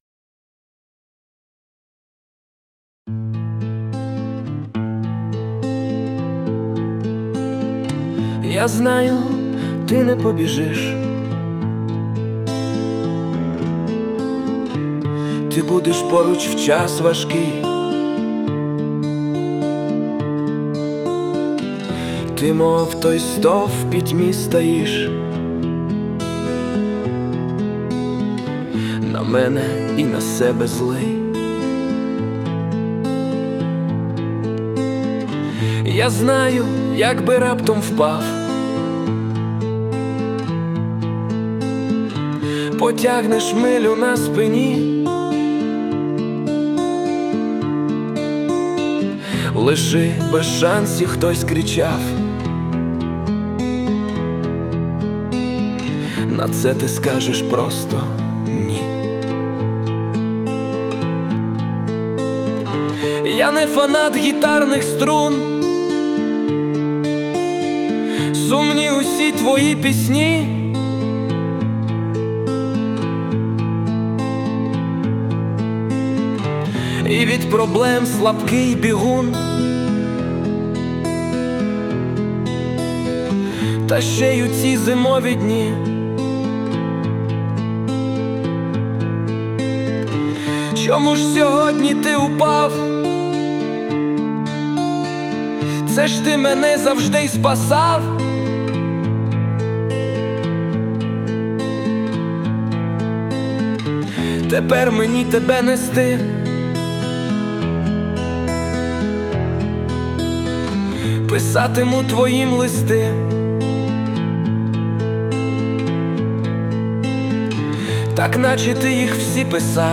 власне виконання
СТИЛЬОВІ ЖАНРИ: Ліричний